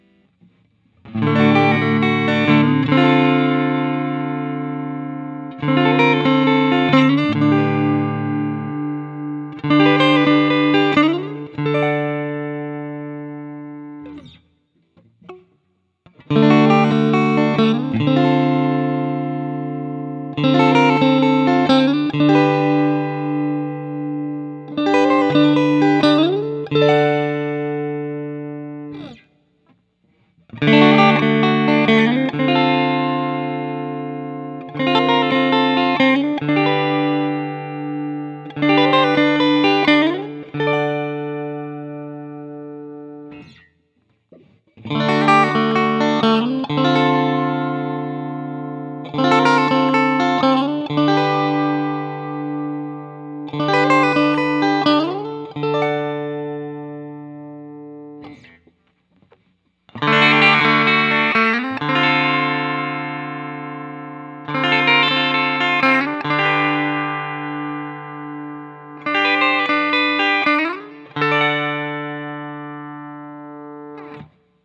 D-Shape Chords
For the first five recordings I had the compressor on and I think it made everything sound a bit flat and harsh because as soon as I turned it off I liked it better, but I’m too lazy to re-record.
For most of the recordings I play my usual test chords through all five of the pickup settings in the following order: